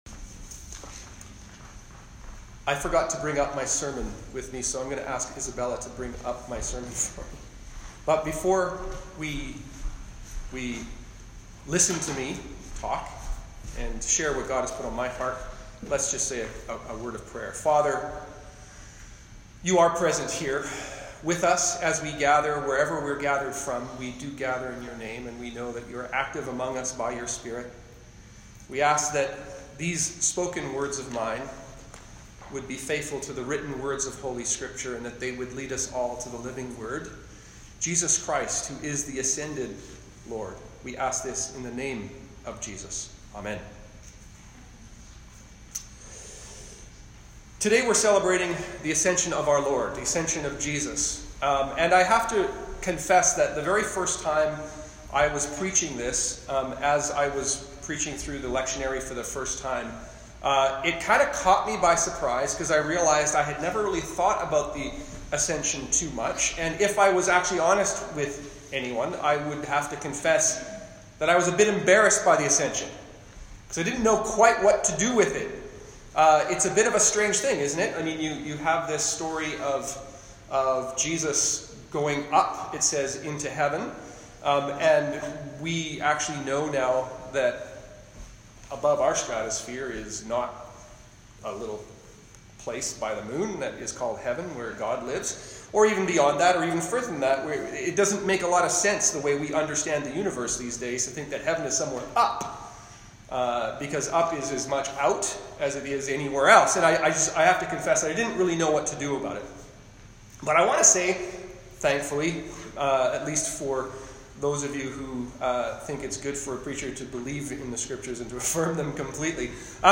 Sermons | St. Paul's Anglican Church
Sermon Notes